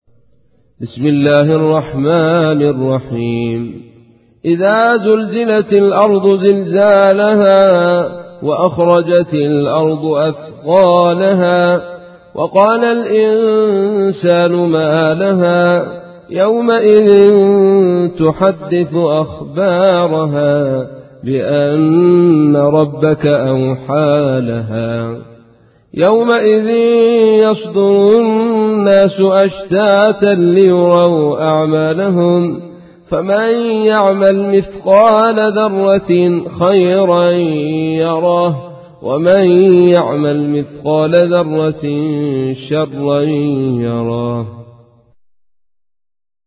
تحميل : 99. سورة الزلزلة / القارئ عبد الرشيد صوفي / القرآن الكريم / موقع يا حسين